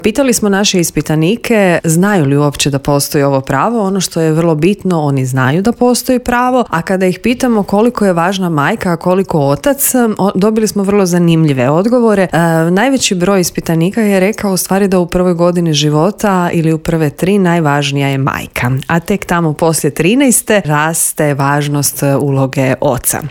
U studiju Media servisa o tome smo razgovarali s državnom tajnicom Željkom Josić koja nam je otkrila što je sve država poduzela da bi se to ostvarilo